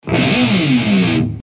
ロックな効果音をご自由にダウンロードして下さい。
Distortion Sound Guitar
Distortionブオーン01 28.04 KB